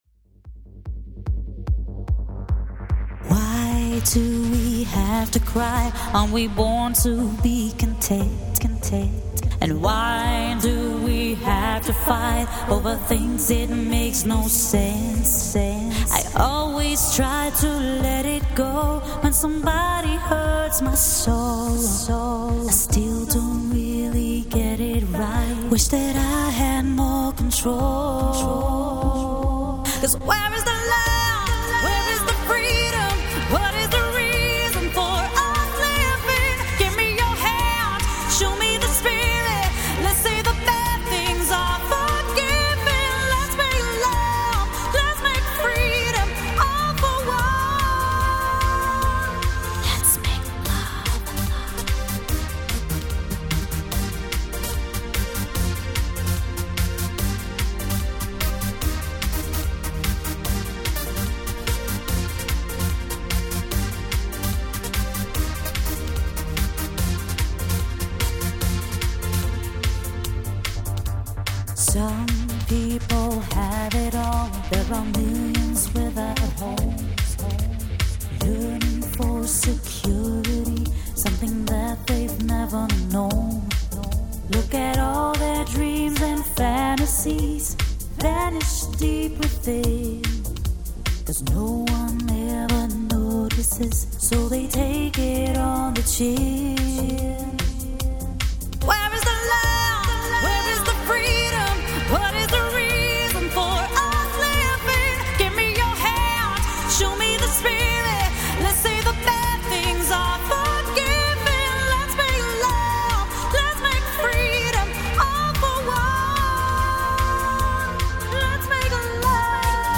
dance/electronic
Trance
Dancehall
Pop